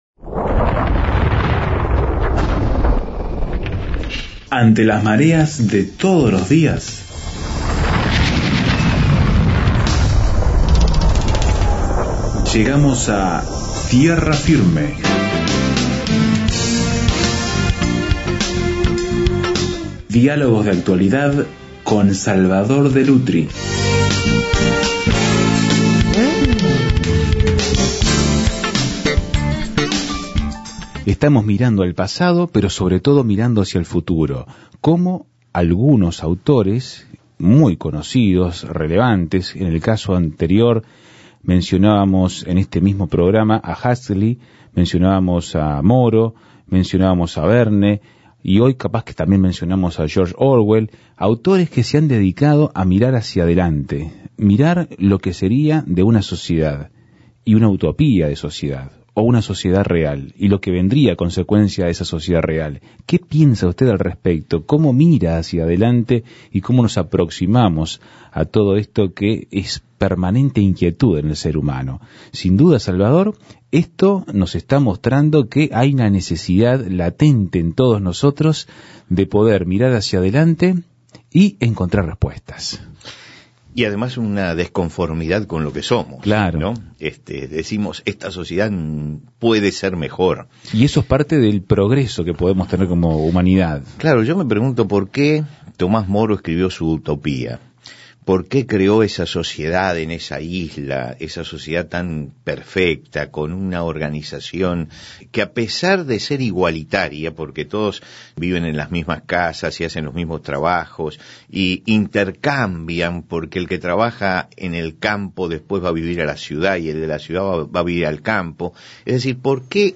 Estamos conversando